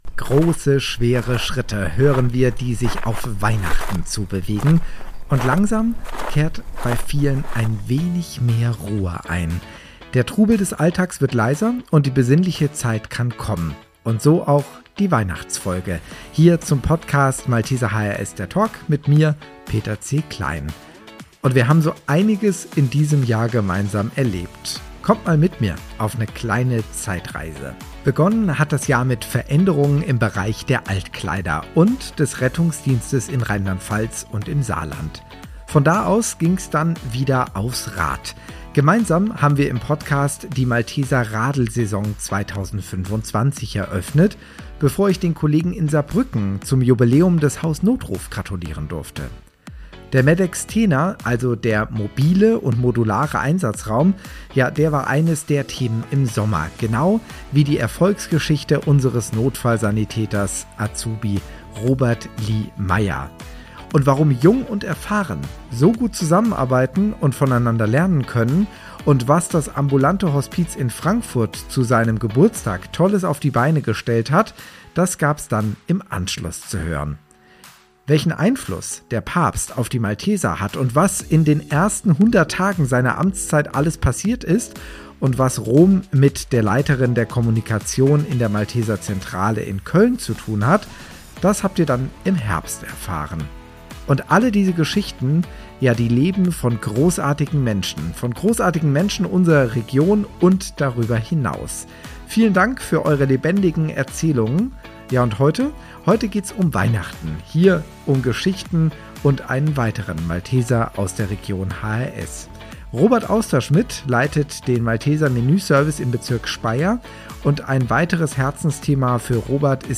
Talk Podcast